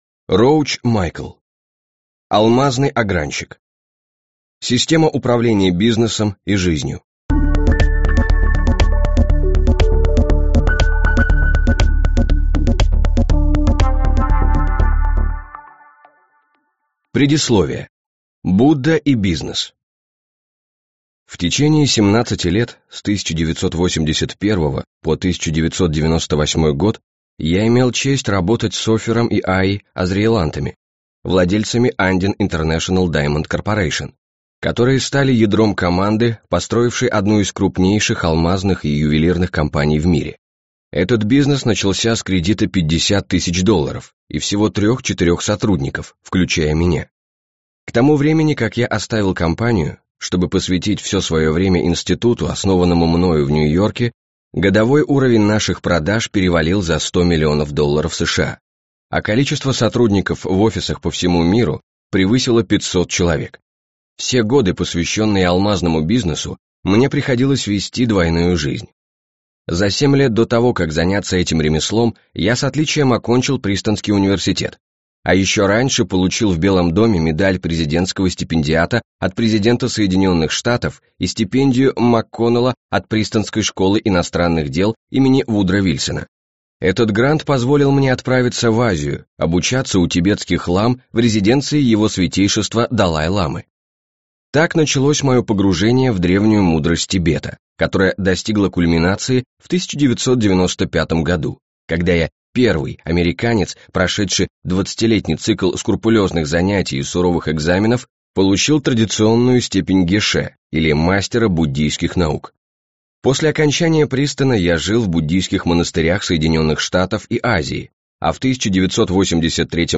Аудиокнига Алмазный Огранщик. Система управления бизнесом и жизнью | Библиотека аудиокниг